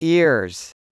8. ears /ɪr/: đôi tai